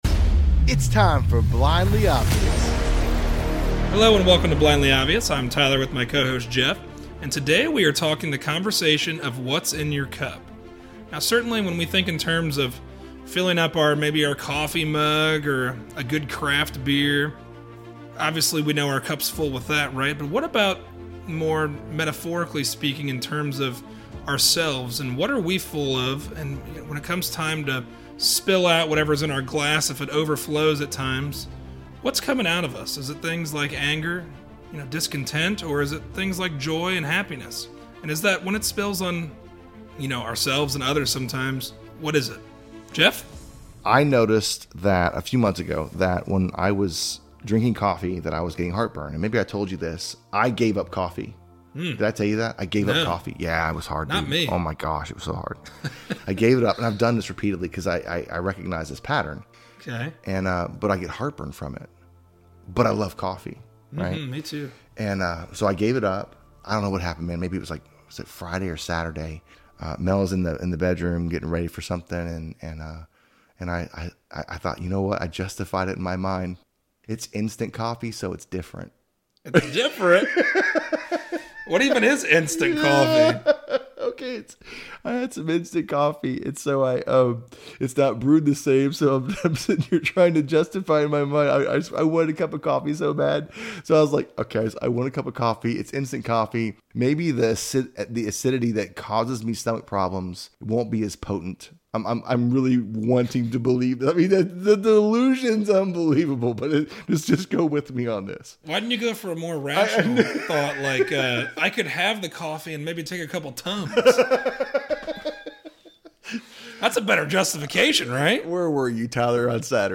A conversation about what’s in your cup.